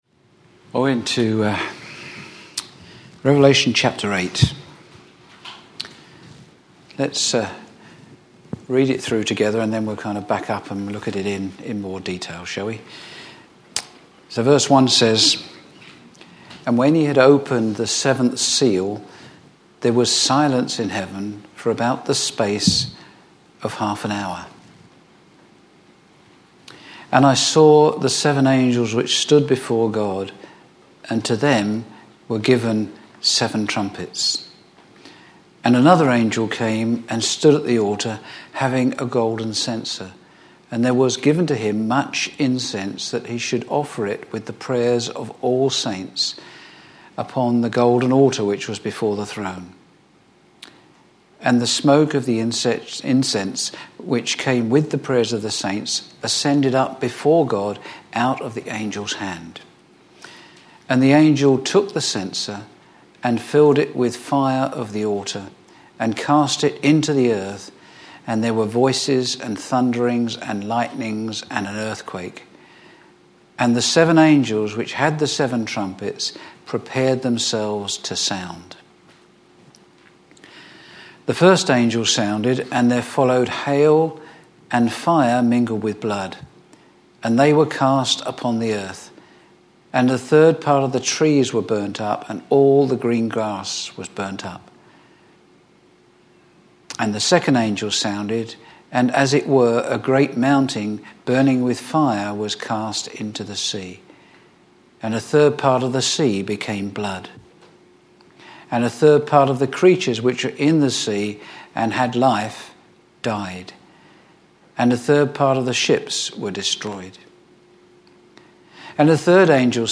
Share this: Click to share on Twitter (Opens in new window) Click to share on Facebook (Opens in new window) Click to share on WhatsApp (Opens in new window) Series: Sunday morning studies Tagged with Verse by verse